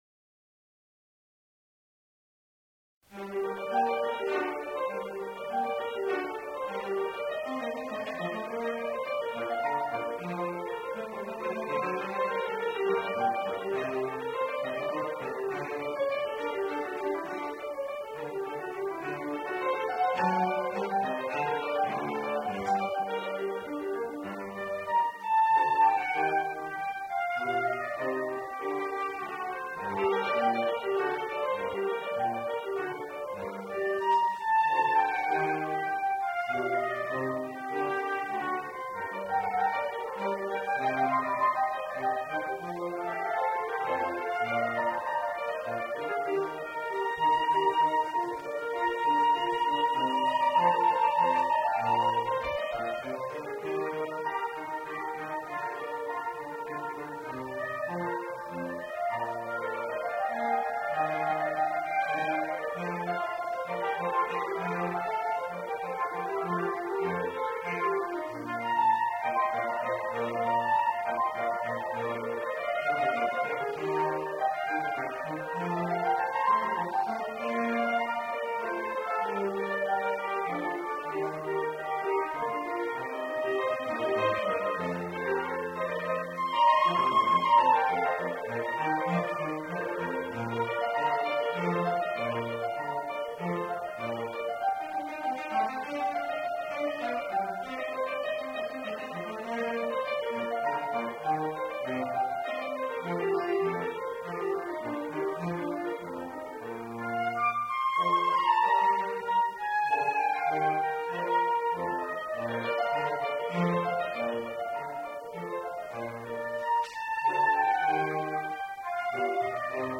FACULTY RECITAL
Grant Recital Hall
Allegro                             Piece Romantique         First Sonata for Flute and Piano                         Trio Op. 119